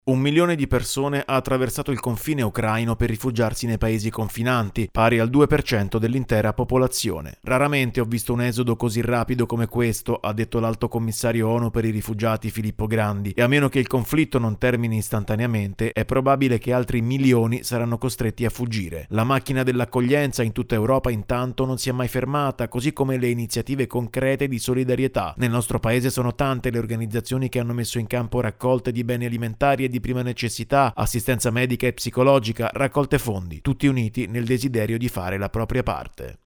Sono già un milione i rifugiati ucraini in Europa: un numero destinato a crescere ancora. Il servizio